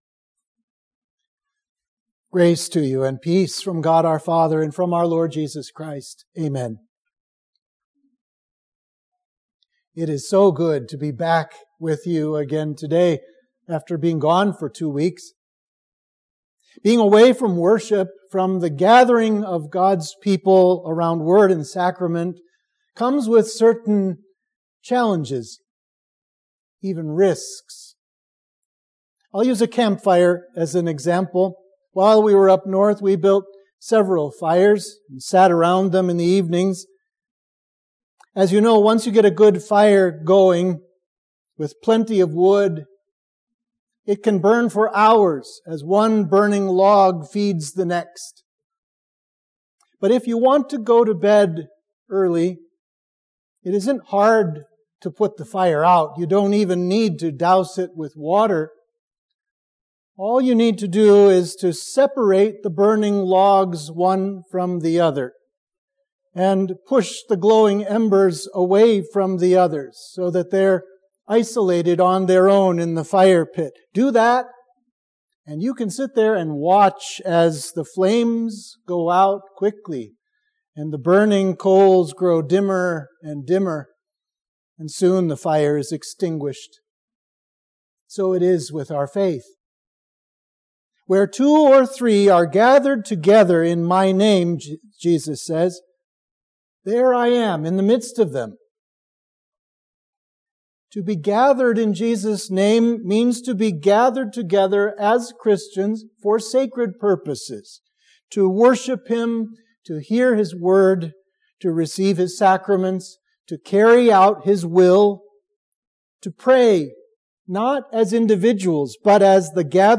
Sermon for Trinity 3